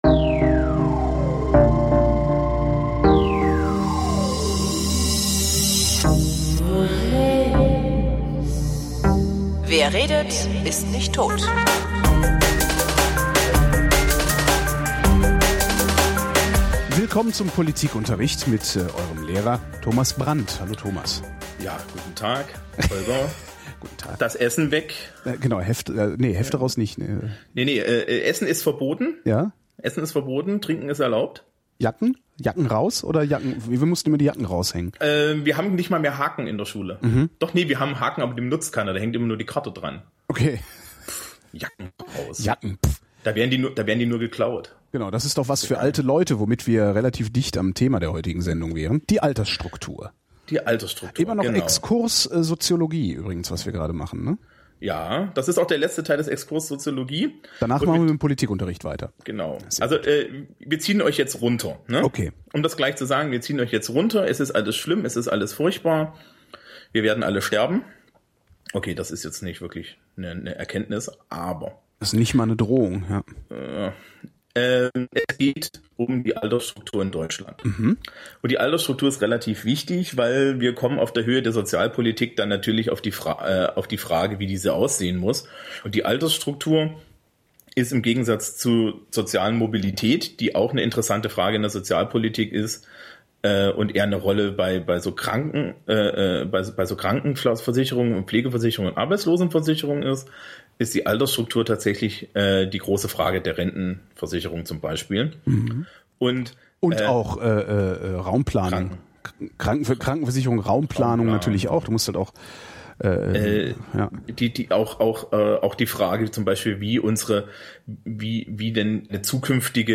Auch diese Folge ist von minderer Klangqualität.